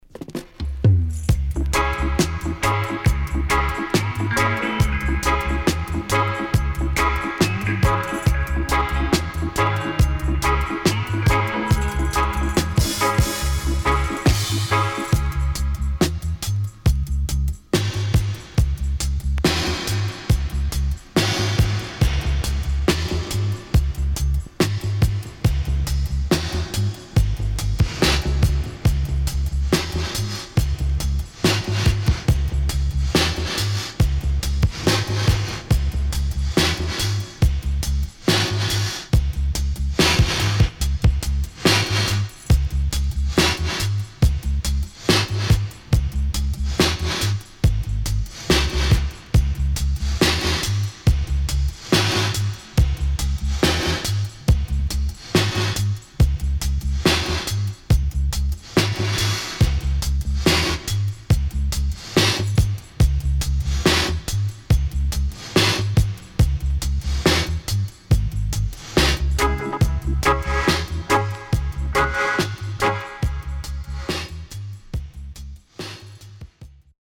SIDE A:序盤、付着物によるノイズ4〜5回あります。
SIDE B:少しノイズ入りますが良好です。